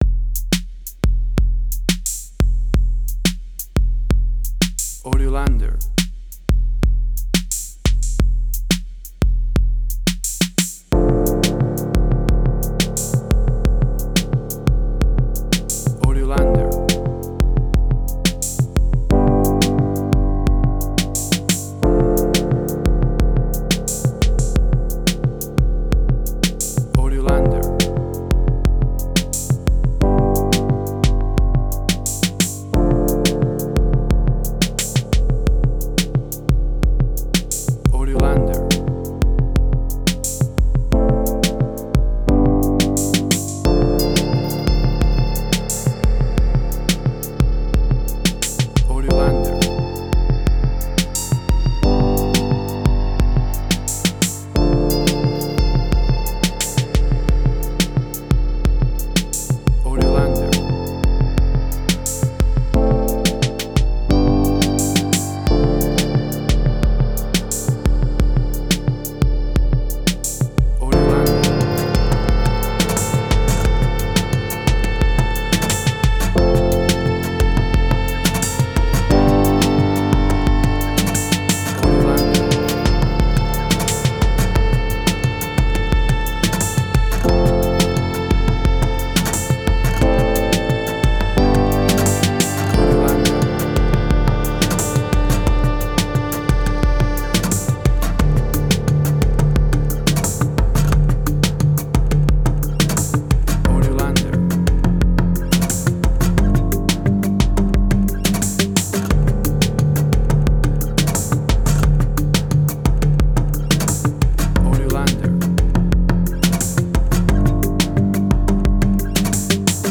Post-Electronic.
emotional music
Tempo (BPM): 88